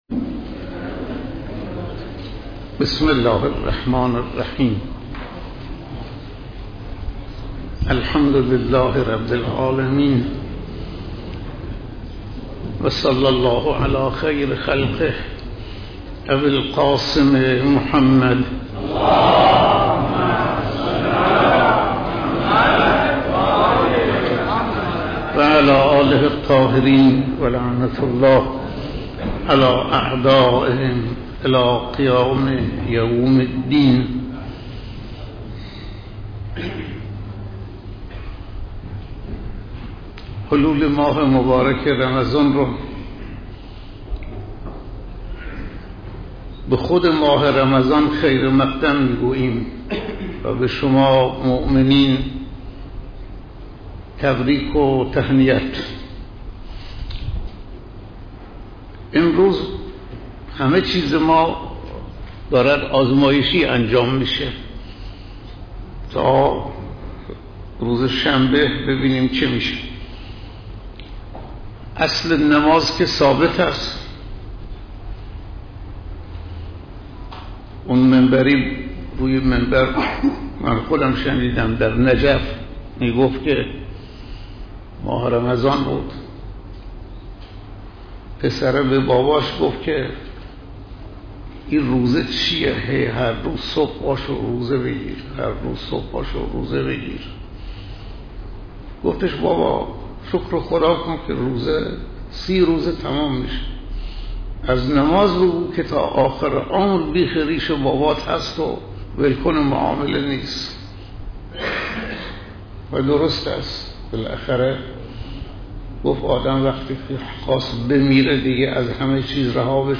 سخنرانی روز اول ماه مبارک رمضان